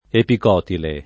[ epik 0 tile ]